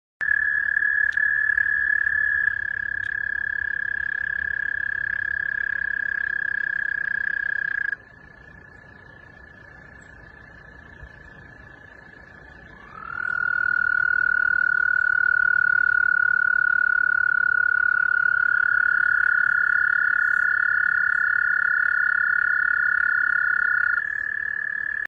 rana